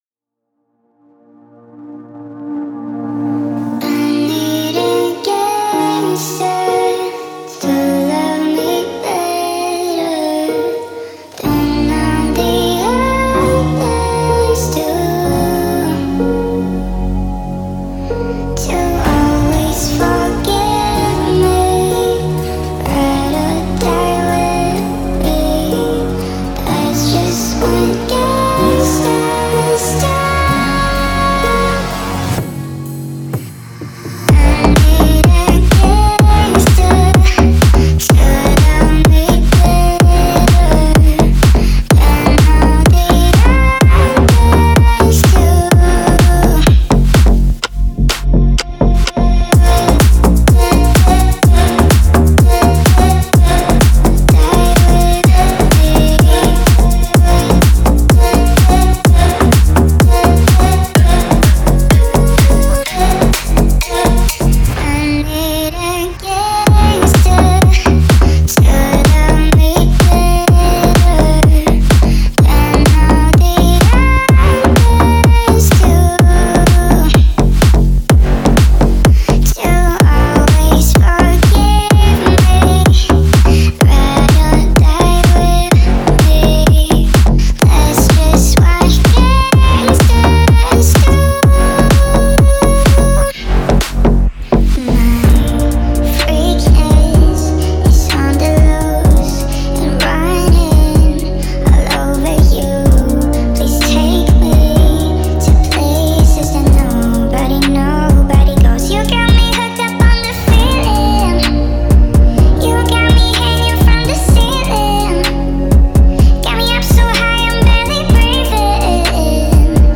это трек в жанре хип-хоп с элементами R&B